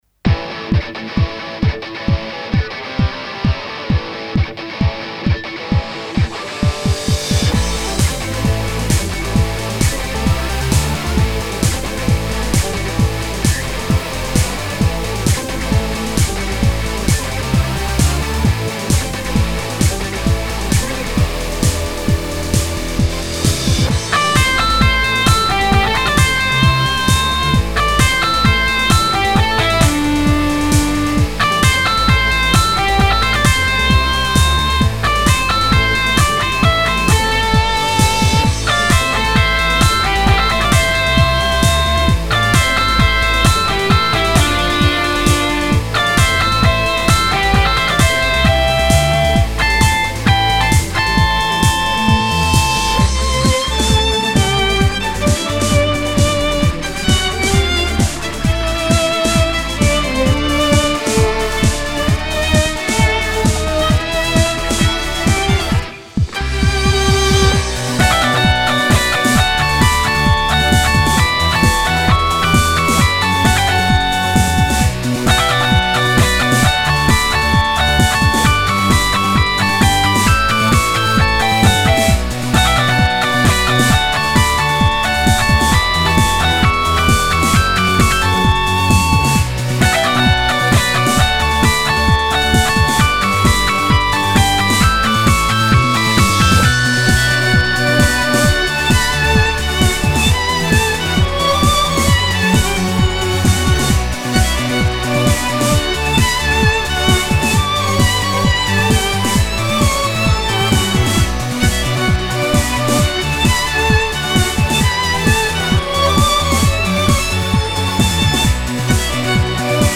フリーBGM バトル・戦闘 4つ打ちサウンド
フェードアウト版のmp3を、こちらのページにて無料で配布しています。